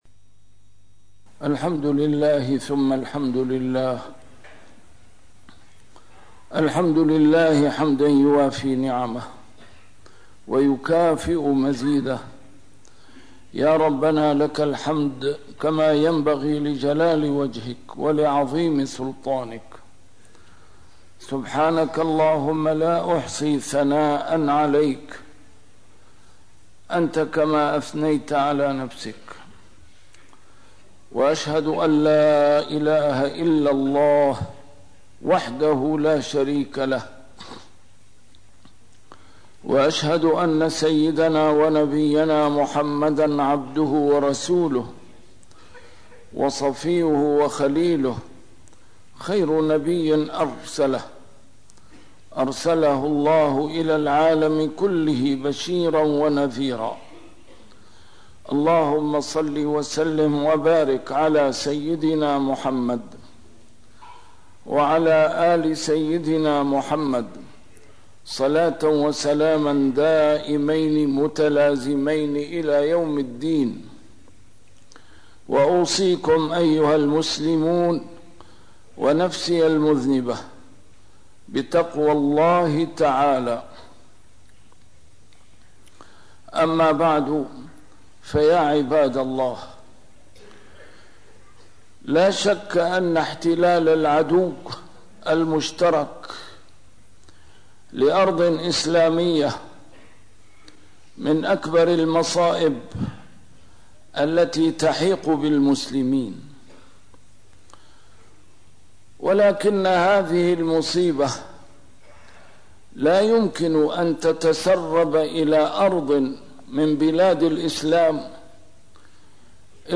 A MARTYR SCHOLAR: IMAM MUHAMMAD SAEED RAMADAN AL-BOUTI - الخطب - تعرف على بذور الفتنة